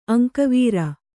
♪ aŋkavīra